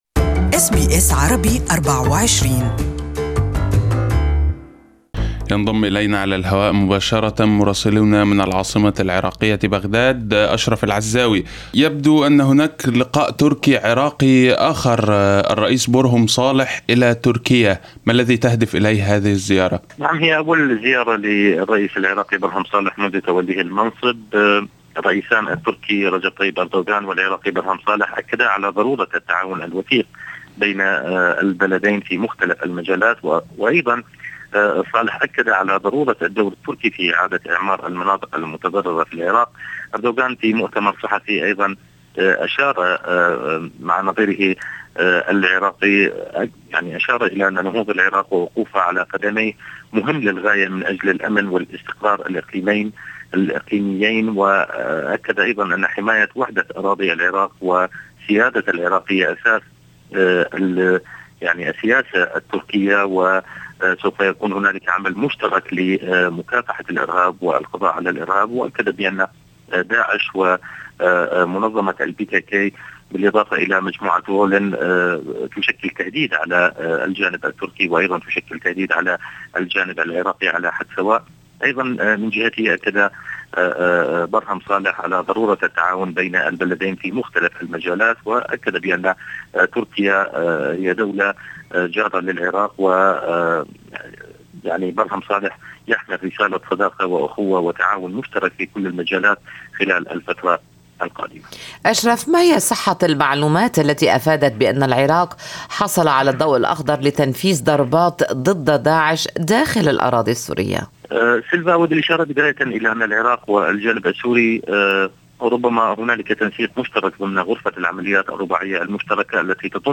Listen to the full report from Baghdad in Arabic above